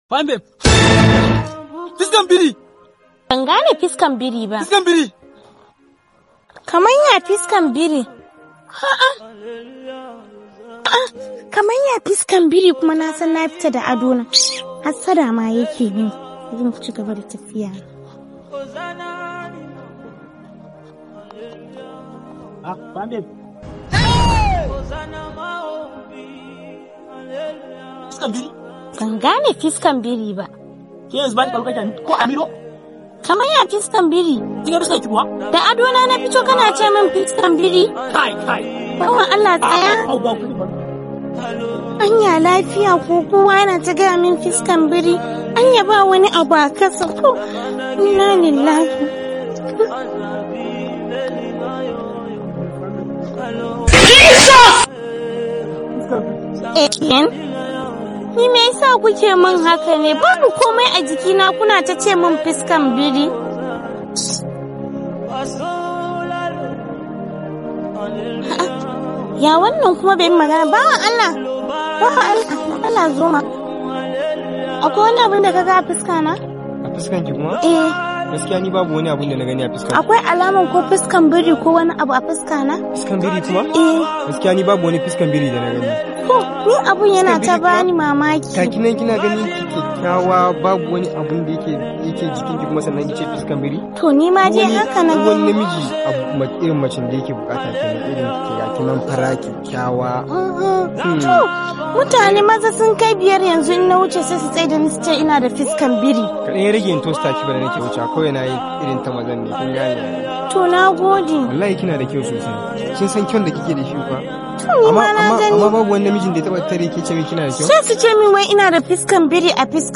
Massage sound effects free download